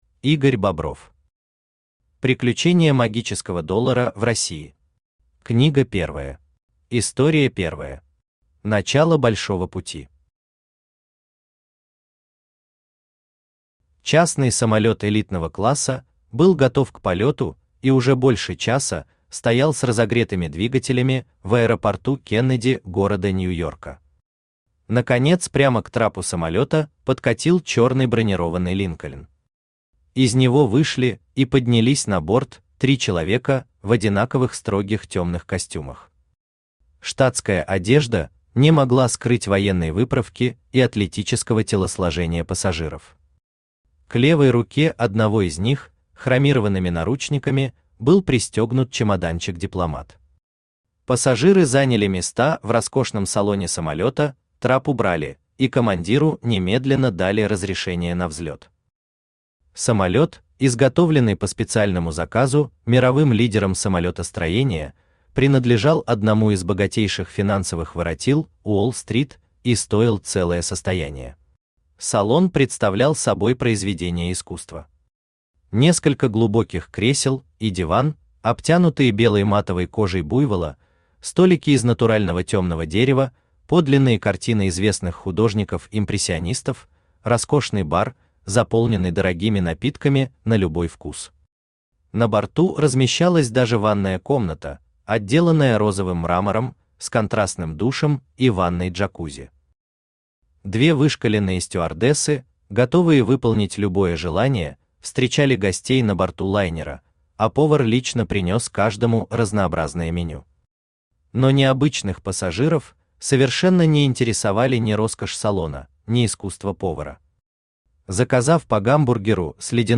Книга первая Автор Игорь Ильич Бобров Читает аудиокнигу Авточтец ЛитРес.